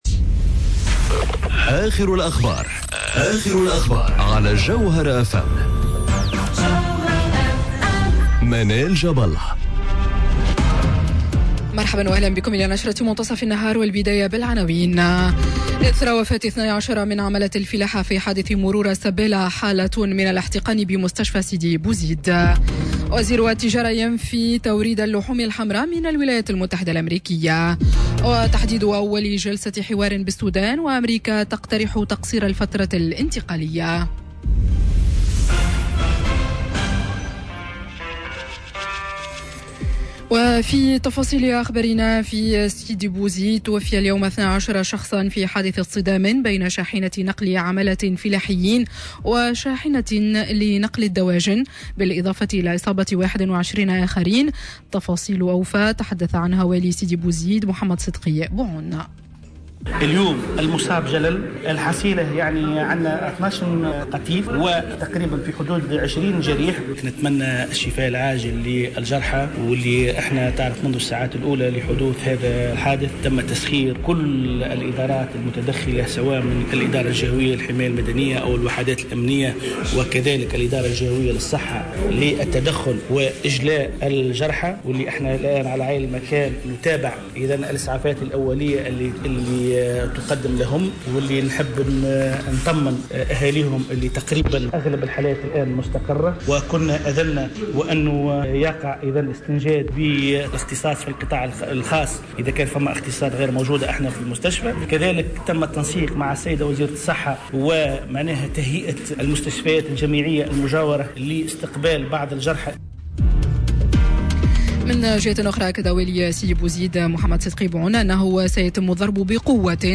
نشرة أخبار منتصف النهار ليوم الخميس 25 أفريل 2019